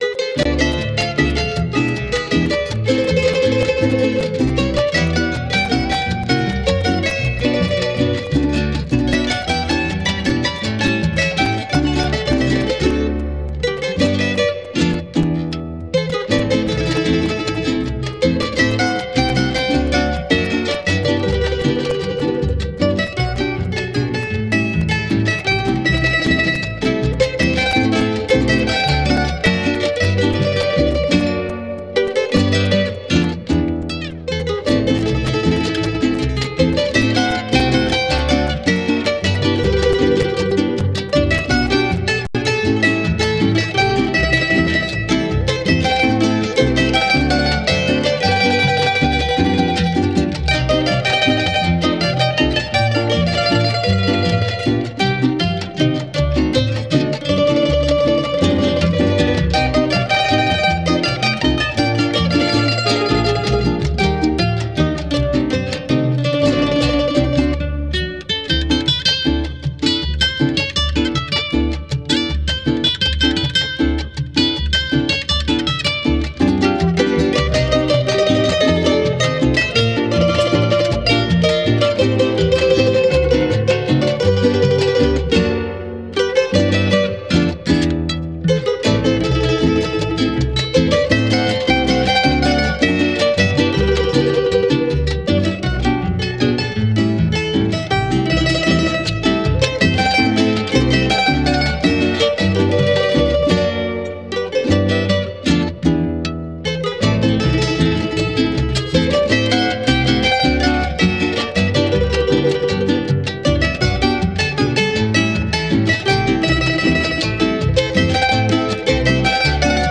Pars Today- La música de América Latina.